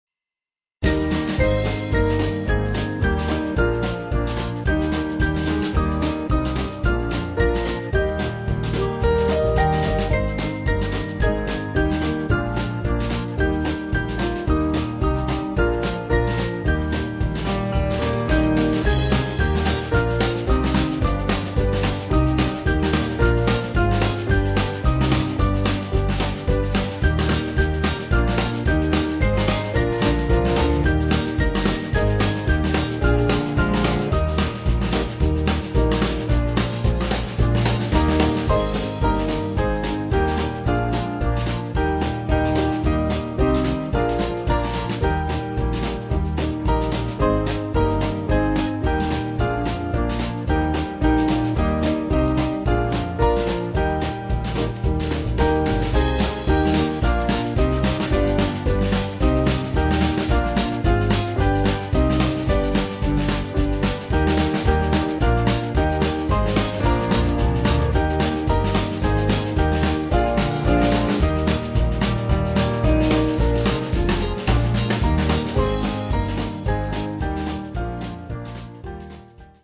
Small Band
4/Eb-E